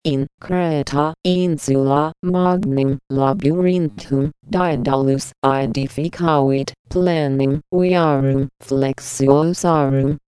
ラテン語文を合成音声で読み上げる技術
北米英語で用いられる音素に対応したものしか存在しないので、これを並べると何を喋っても北米訛りになる、という弱点が存在しますが、その点には目を瞑って頂ければ幸いです。
ちなみに母音の長短は（英語側で対応する短母音／長母音ではなく）音の長さで表しています。
ラテン語は高低アクセントなので、アクセントはとりあえず周波数の高低で表現することができます。
speak_latin.py の実装では、低い方を135Hz（CとC#の間ぐらい？）、高い方をその4/3倍（低い音の4度上に相当）にしています。